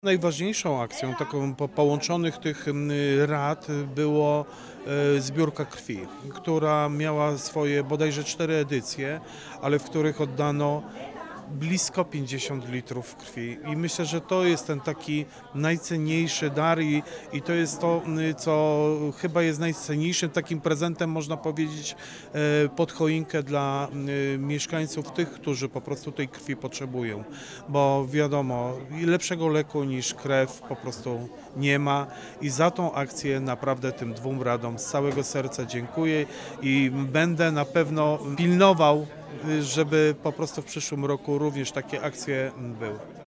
Starosta Powiatu Wrocławskiego Włodzmierz Chlebosz zwraca uwagę na wymiar praktyczny współpracy międzypokoleniowej.